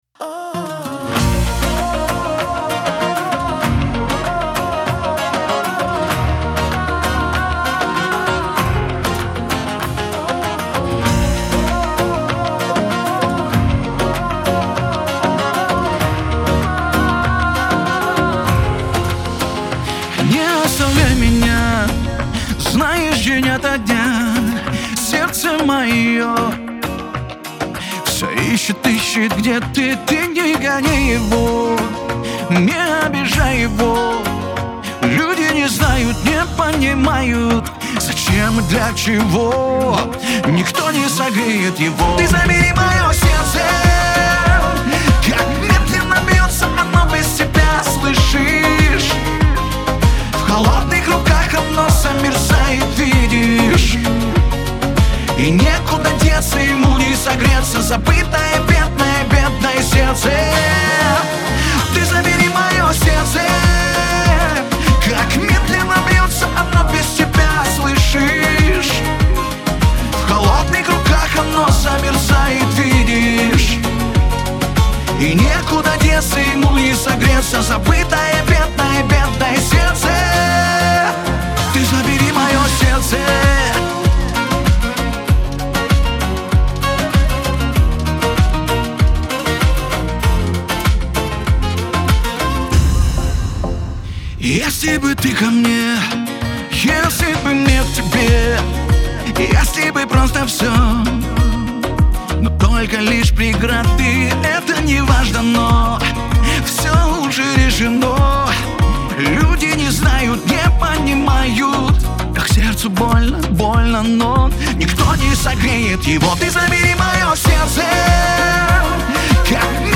Лирика , эстрада
диско